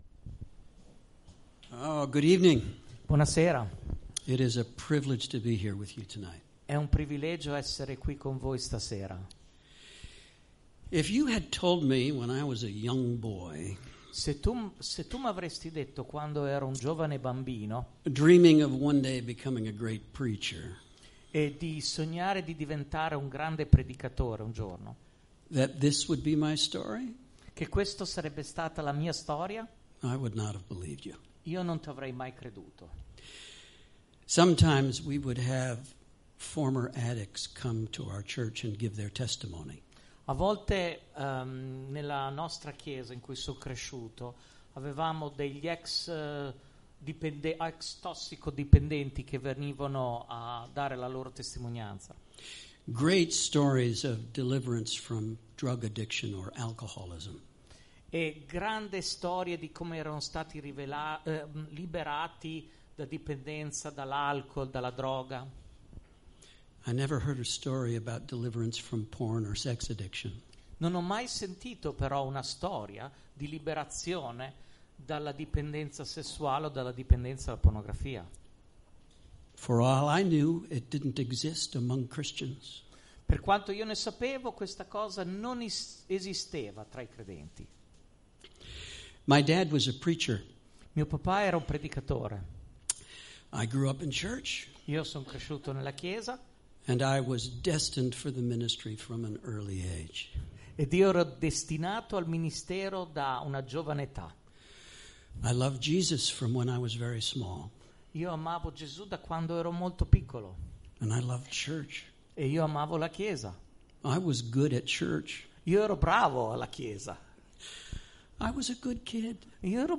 Traduzione